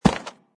grassstone2.mp3